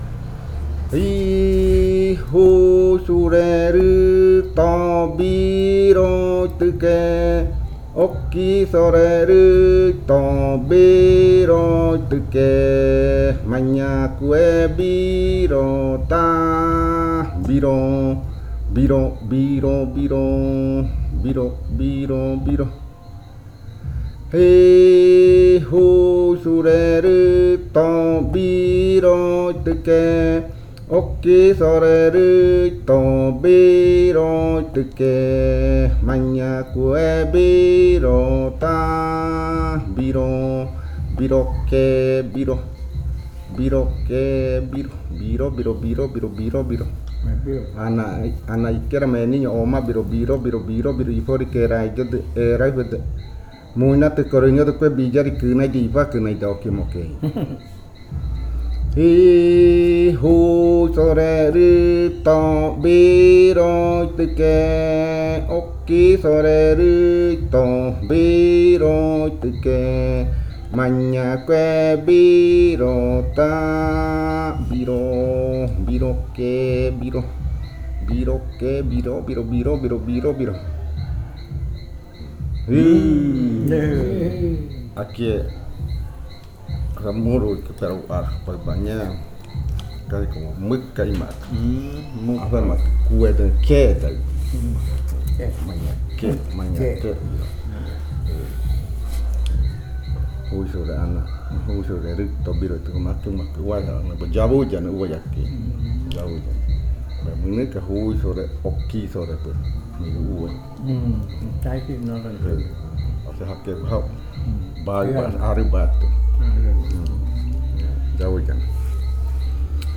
Leticia, Amazonas, (Colombia)
Grupo de danza Kaɨ Komuiya Uai
Canto fakariya de la variante Muruikɨ (cantos de la parte de arriba).
Fakariya chant of the Muruikɨ variant (Upriver chants).